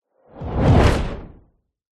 На этой странице собраны звуки ударной волны после взрыва — от глухих ударов до резких перепадов давления.
Звук удара мощного воздушного потока в металл, взрывная сила